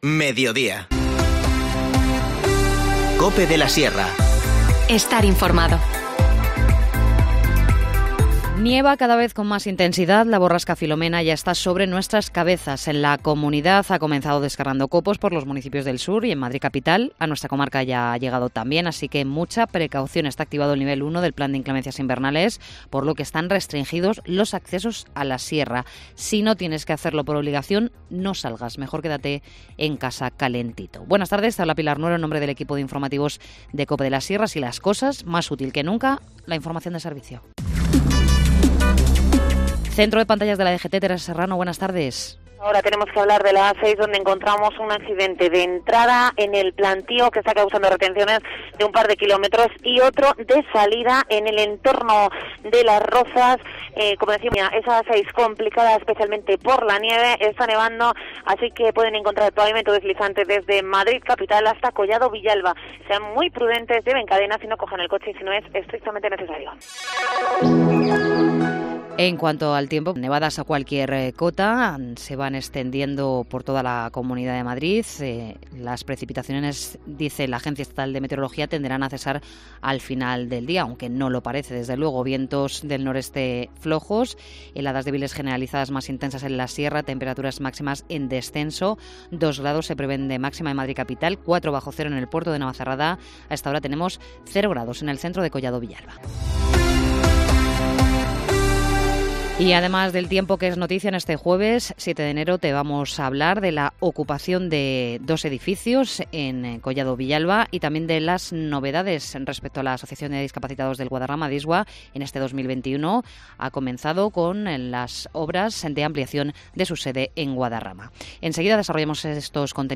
Informativo Mediodía 7 enero
INFORMACIÓN LOCAL